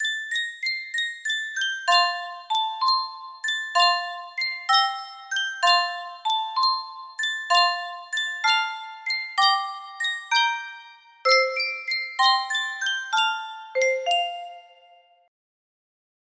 Sankyo Music Box - Hickory Dickory Dock OQ music box melody
Full range 60